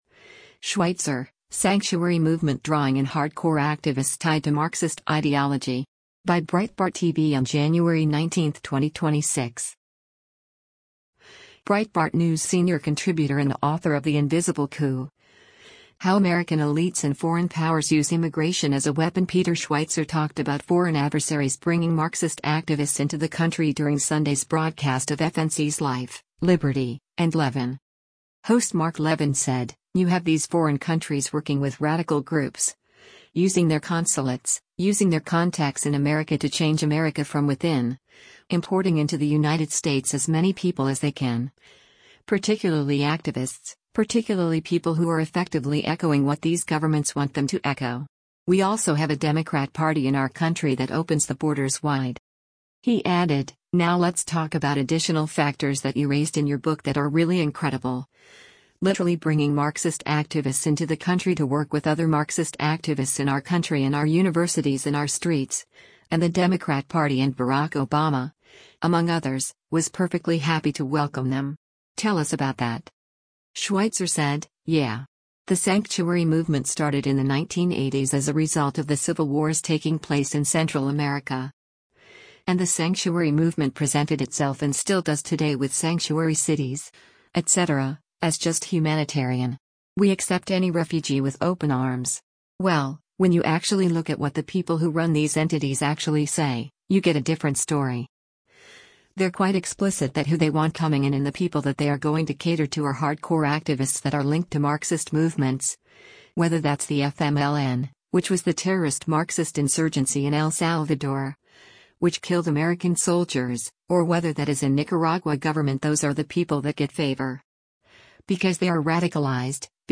Breitbart News senior contributor and author of The Invisible Coup: How American Elites and Foreign Powers Use Immigration as a Weapon Peter Schweizer talked about foreign adversaries bringing Marxist activists into the country during Sunday’s broadcast of FNC’s “Life, Liberty, and Levin.”